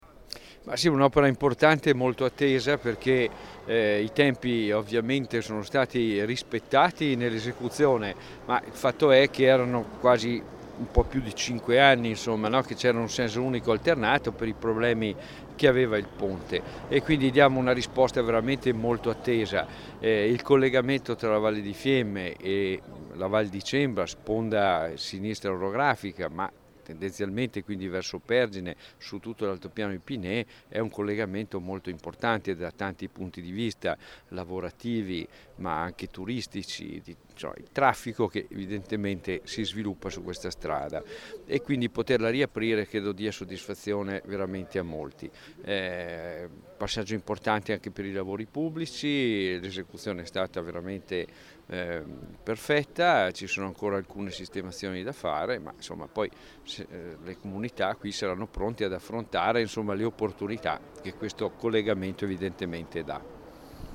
Stamattina la cerimonia di inaugurazione dell'opera costata 3,2 milioni di euro alla presenza dell'assessore Mauro Gilmozzi
Ass_Gilmozzi_ponte_Stramentizzo_MP3_256K.mp3